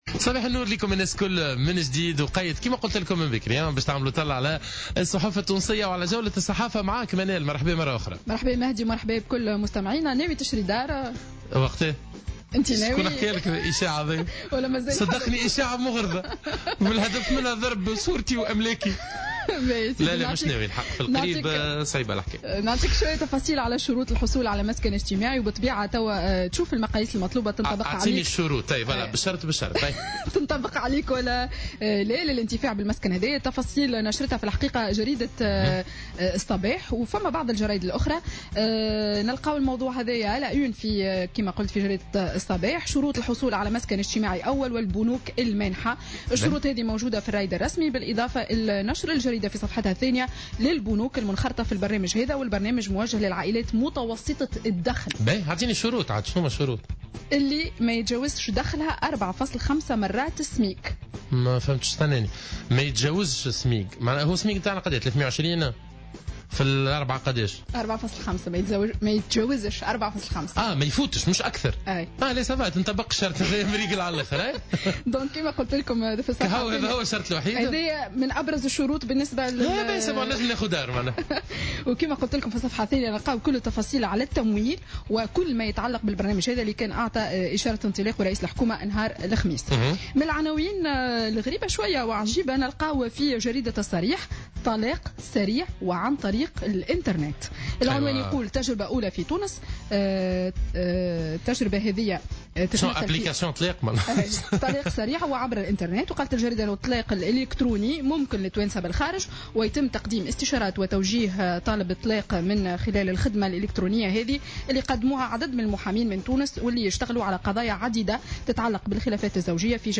Revue de presse du samedi 4 Février 2017